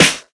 Snares
Lef_Snr.wav